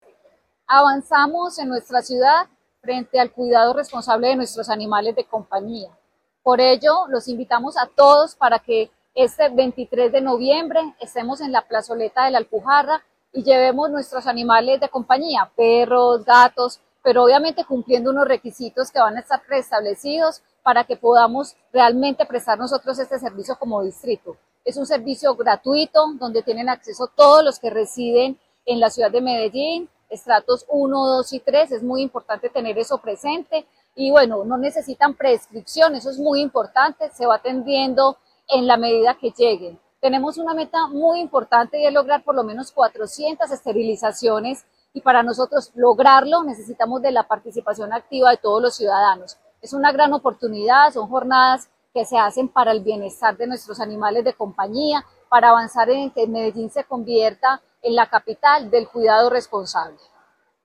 Palabras de Ana Ligia Mora, secretaria de Medio Ambiente En su compromiso con el bienestar animal y la adopción responsable, la Alcaldía de Medellín llevará a cabo este sábado y domingo dos grandes eventos enfocados en la protección de los animales.